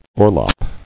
[or·lop]